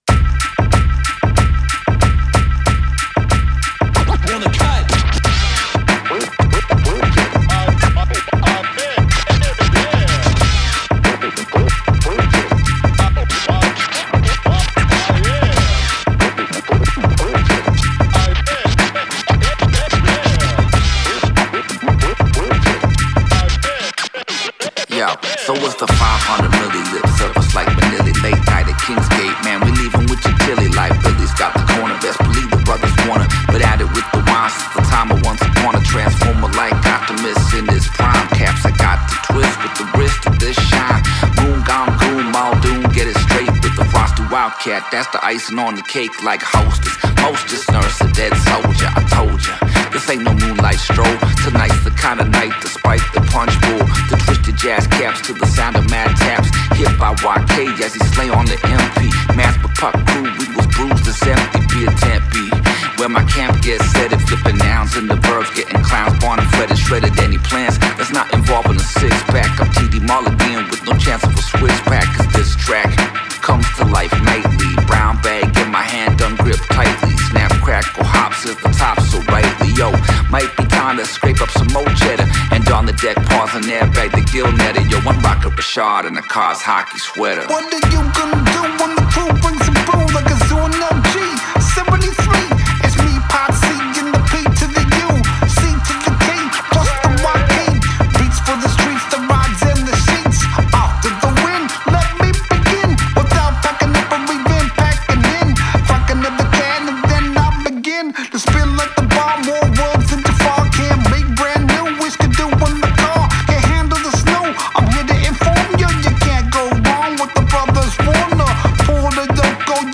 boom-bap goodness
Three tracks, zero filler, maximum head-nod.